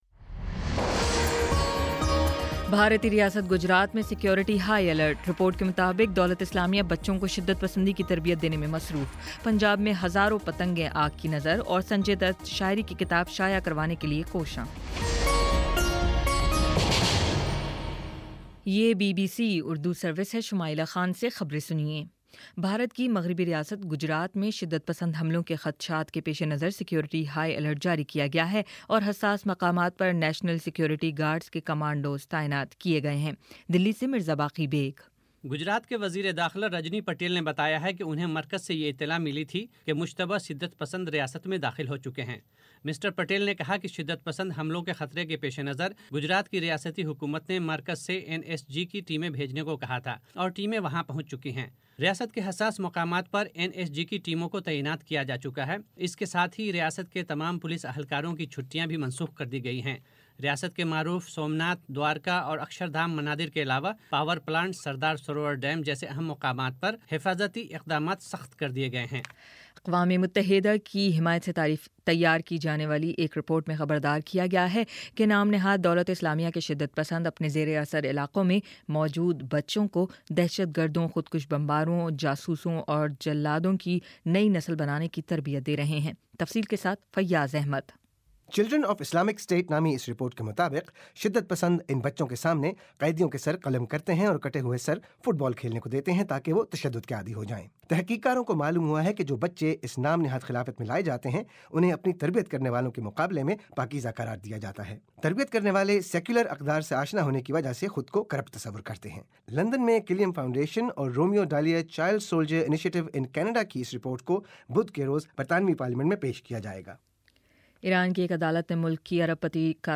مارچ 06 : شام چھ بجے کا نیوز بُلیٹن